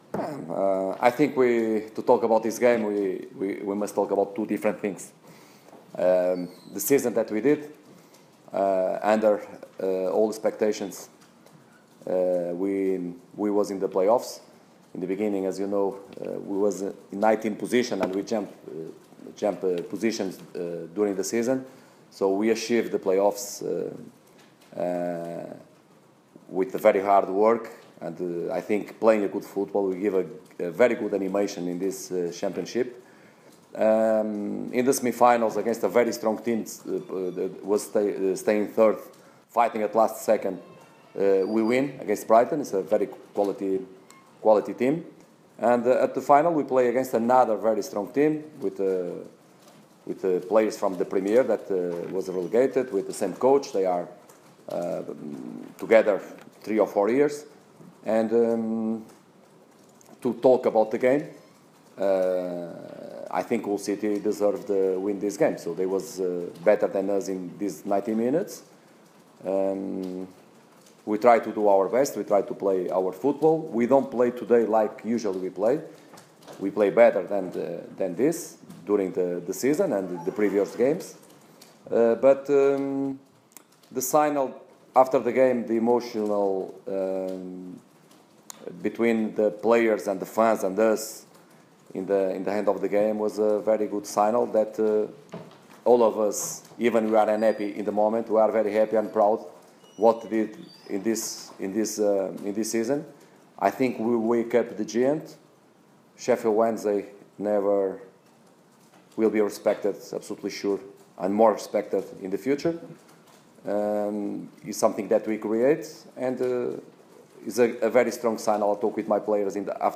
Sheffield Wednesday head coach Carlos Carvalhal speaks to the assembled media after the Owls loss 1-0 at Wembley in the Championship Playoff Final.